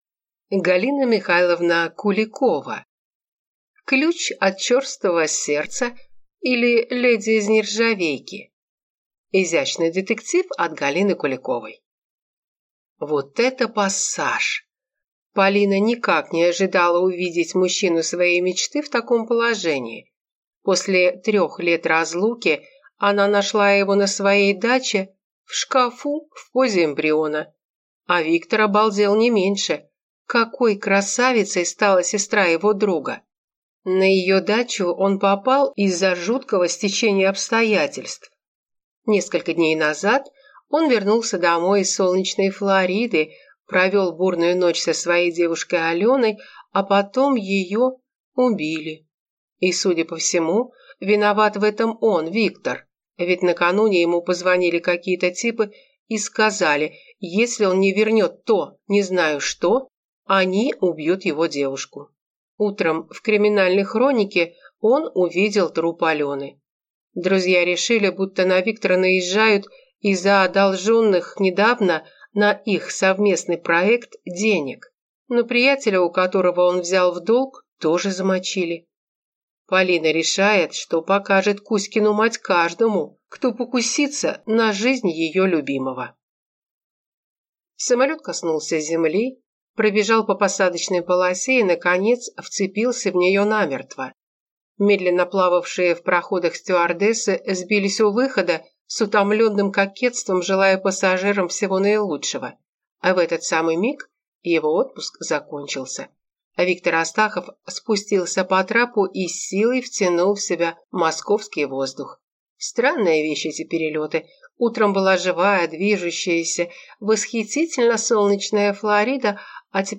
Аудиокнига Ключ от черствого сердца, или Леди из нержавейки | Библиотека аудиокниг